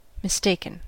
Ääntäminen
Synonyymit erroneous Ääntäminen US Tuntematon aksentti: IPA : /mɪsˈteɪkən/ Haettu sana löytyi näillä lähdekielillä: englanti Mistaken on sanan mistake partisiipin perfekti.